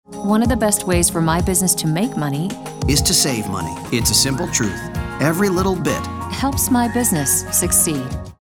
Major Financial Institution with the Dedication of a Community Bank Hires ‘Everyman’ Voice Talent
Listen to a sample of the campaign.
FFB_SimpleTruth_RADIO_edit.mp3